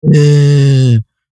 Trump whine